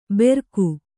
♪ berku